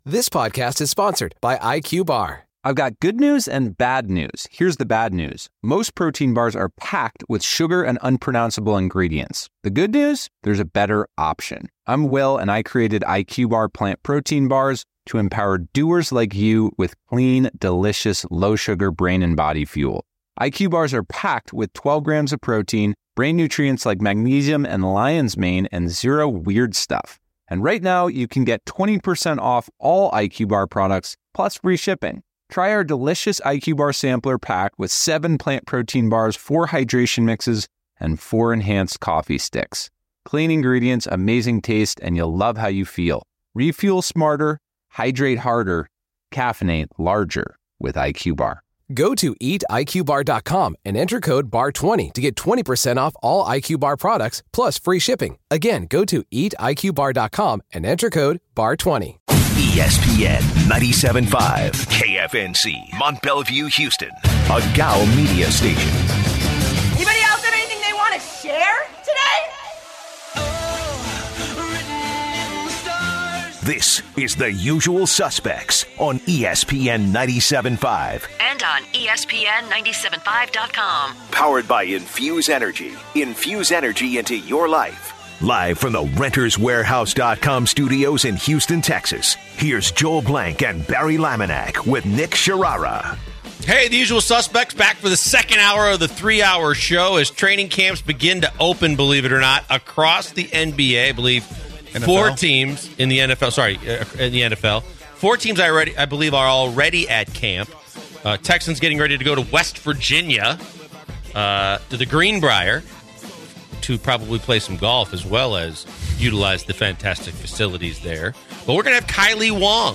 comes into the studio. They finish the hour with talk about kids playing football getting recruited younger and younger by colleges.